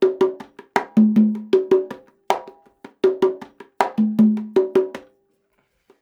80 CONGA 1.wav